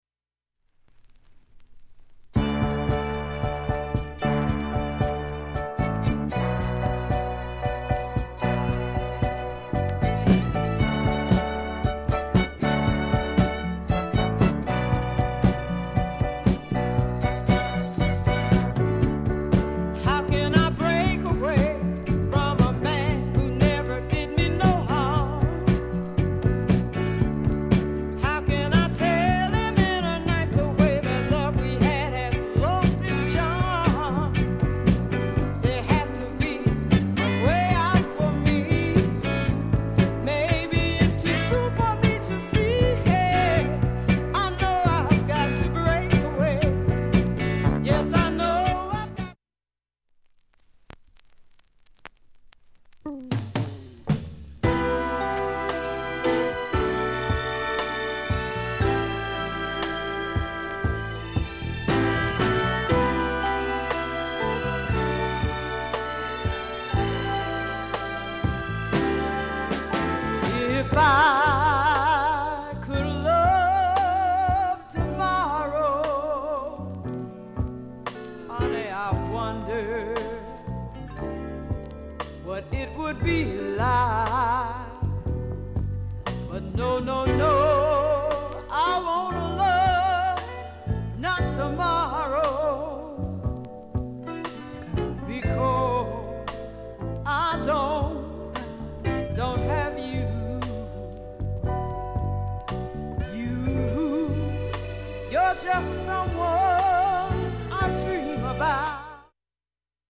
オリジナル現物の試聴（両面１コーラス程度）できます。